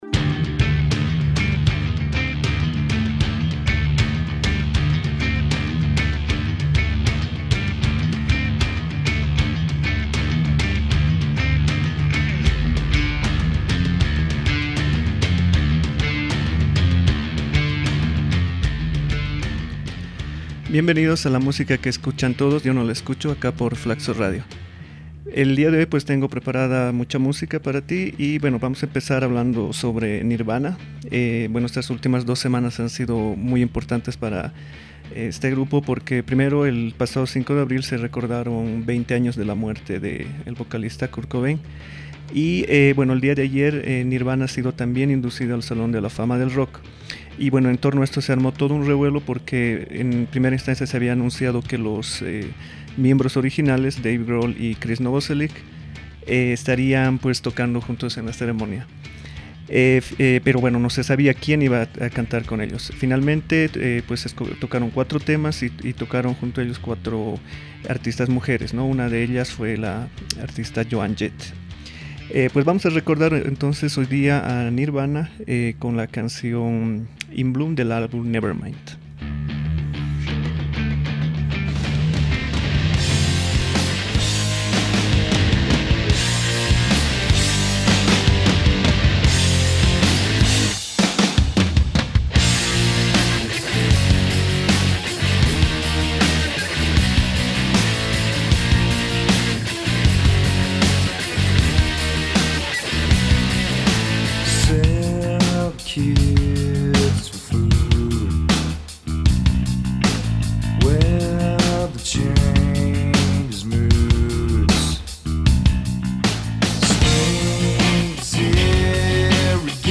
¿Metal latinoamericano?